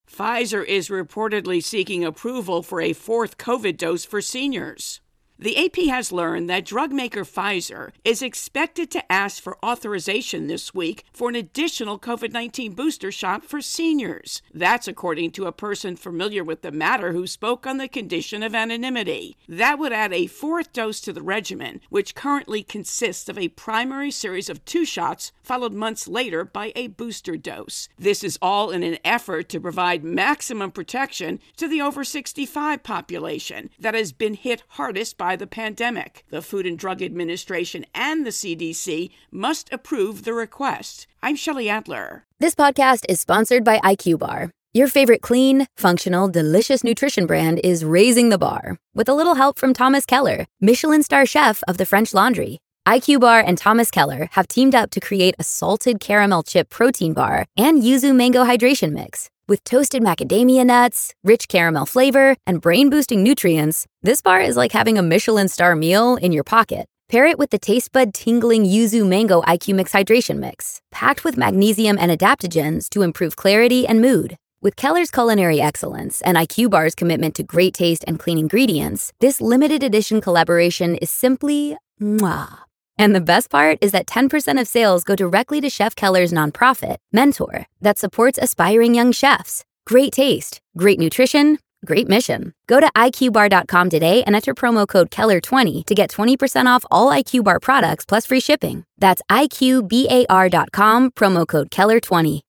4th dose intro and voicer